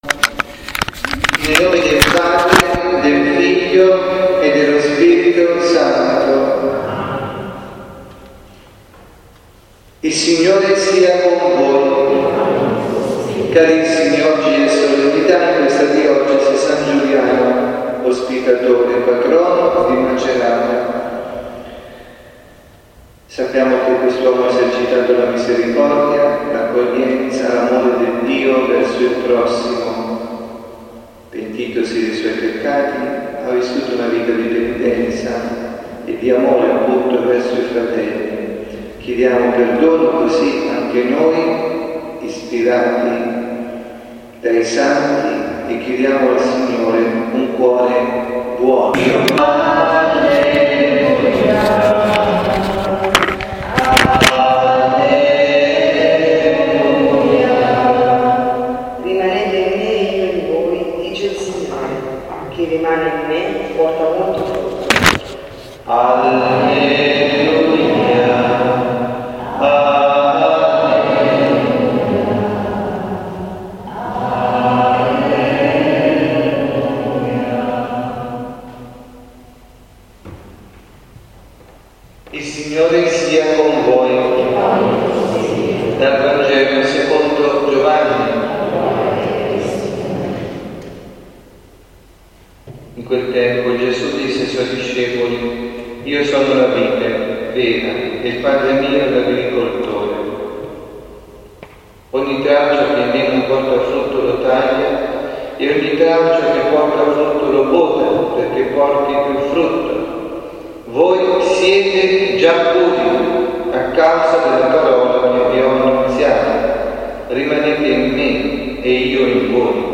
Lectio
dalla Parrocchia S. Rita, Milano